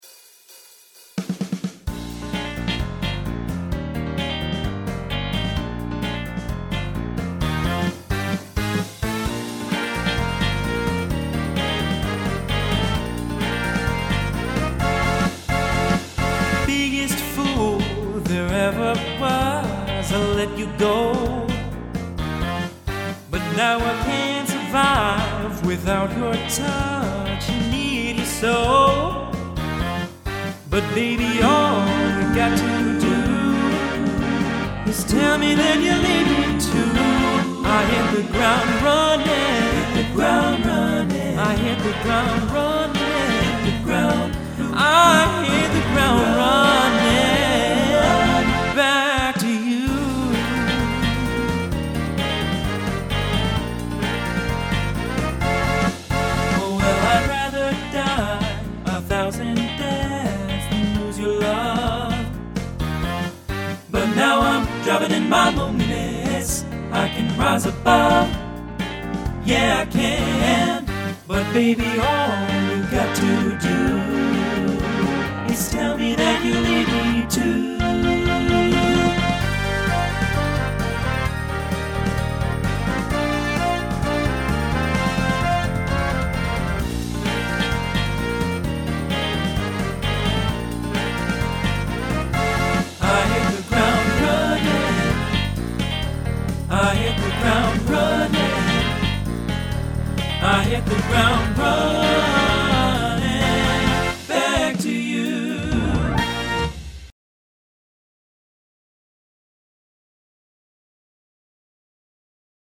Genre Pop/Dance , Rock Instrumental combo
Transition Voicing TTB